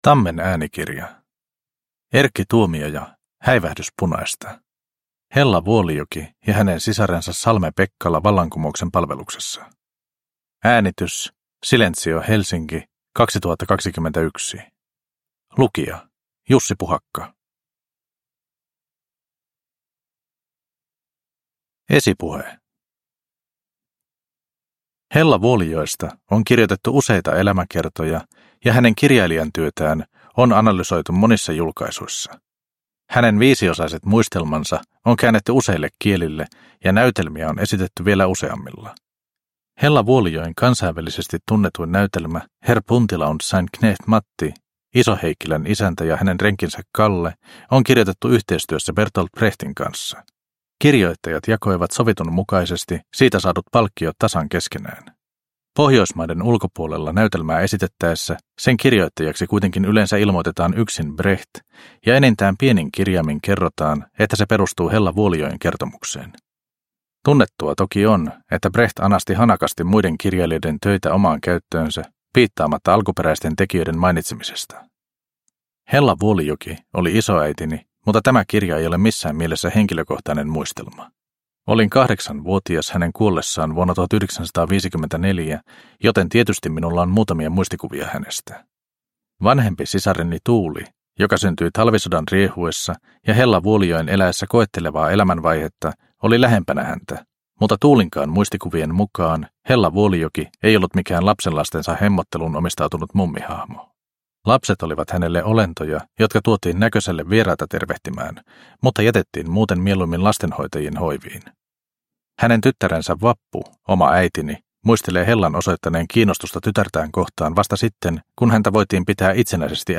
Häivähdys punaista – Ljudbok